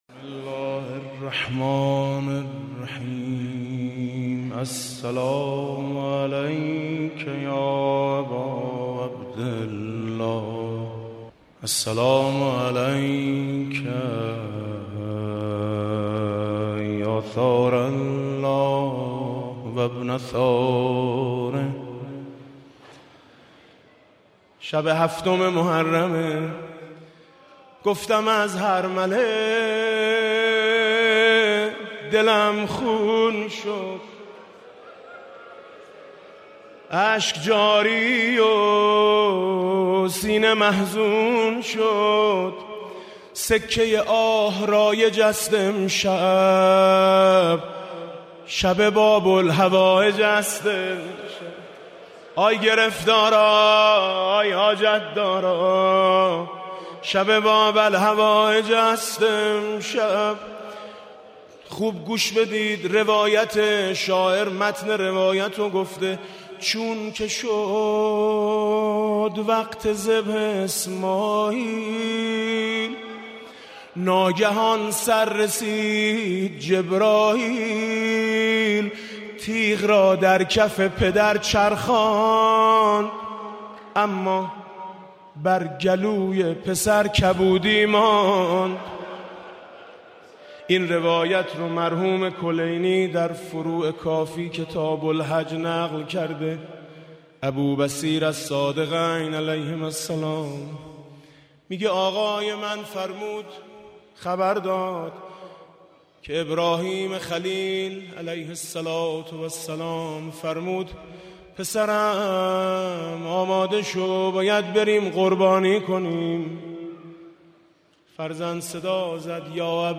بیت رهبری [حسینیه امام خمینی (ره)]
مناسبت: شب هفتم محرم
با نوای: حاج میثم مطیعی
آی دنیا بیا تماشا کن (روضه)